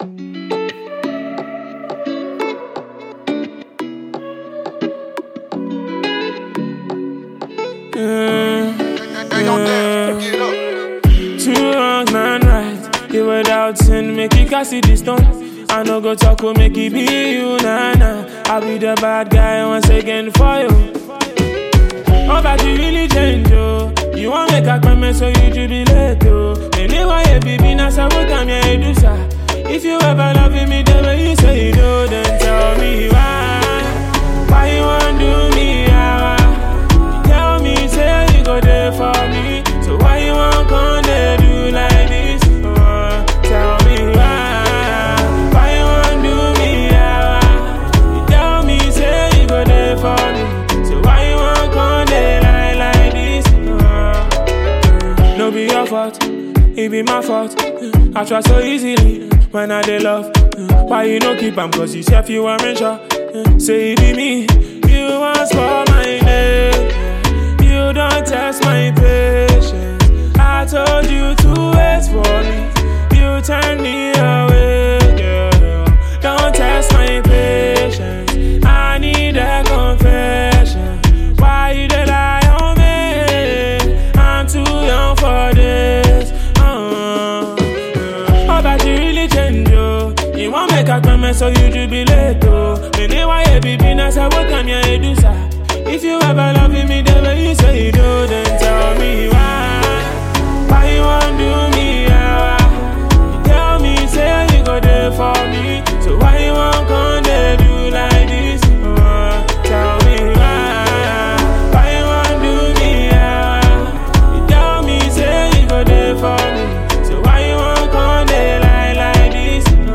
Multi-Skilled Ghanaian rapper and singer